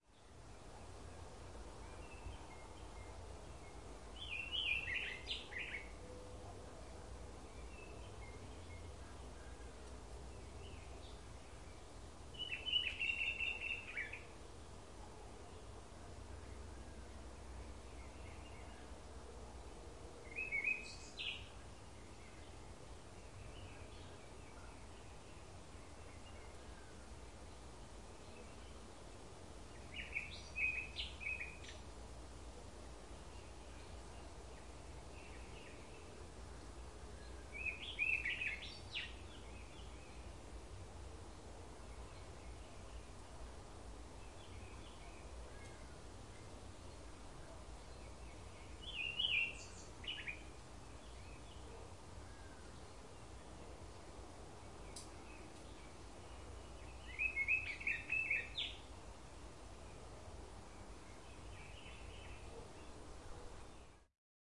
早晨的氛围与鸟叫声
描述：早上5点30分左右在后院拍的声音
Tag: 非洲 掌握 啁啾 早晨 鸣叫 花园 OWI 南非洲 镇静